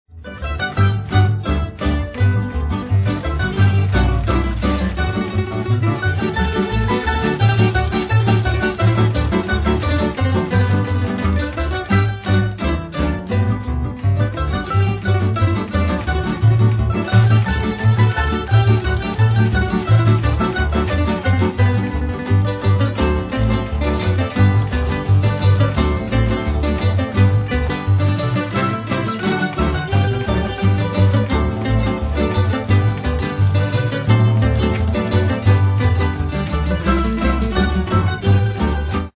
Audio zapis pjesama u izvođenju društva. Cd je snimljen u prostorijama Osnovne škole Novi Marof tijekom ljeta 2002. godine.
Polke (narodna)